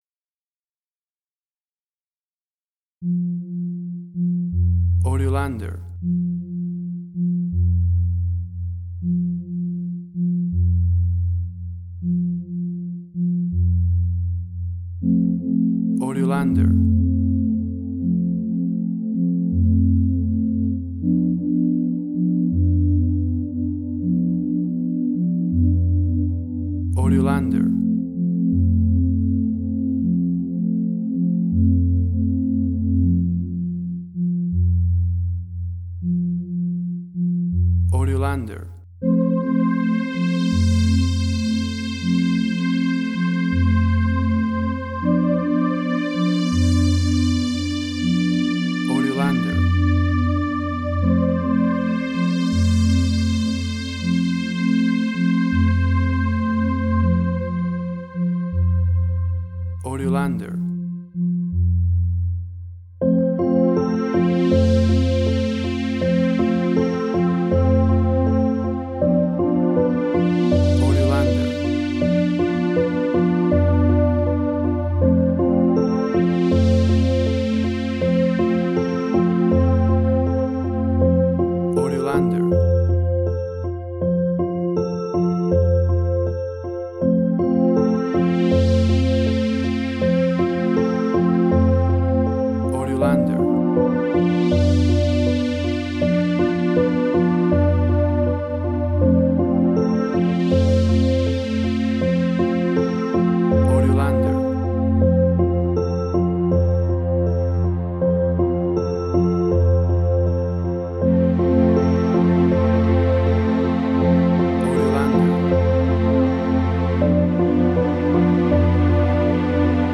New Age
Tempo (BPM): 80